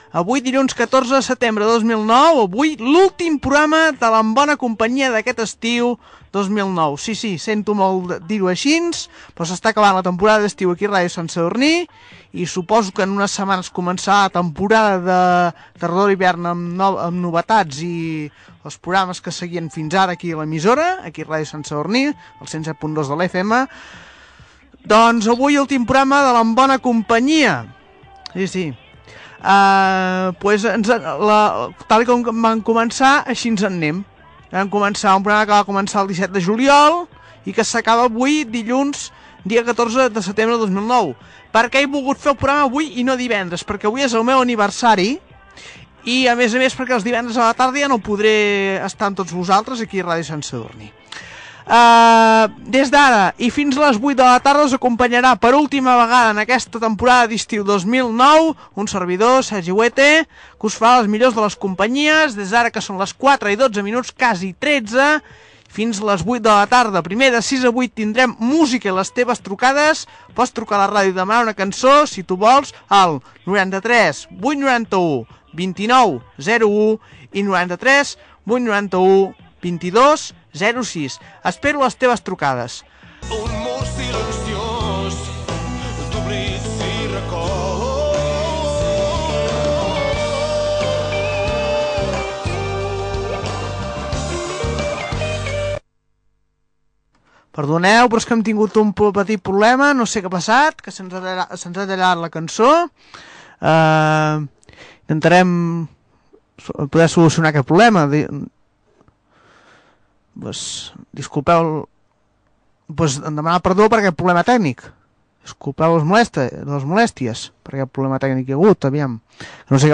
Data, presentació de l'últim programa, telèfon, tema musical amb un problema tècnic en la seva reproducció
Entreteniment